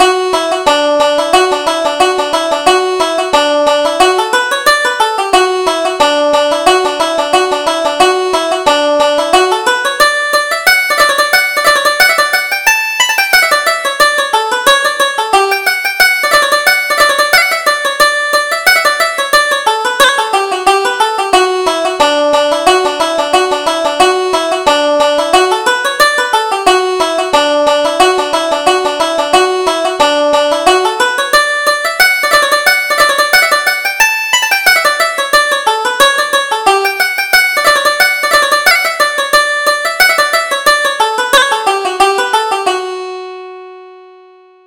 Reel: The Lisburn Lasses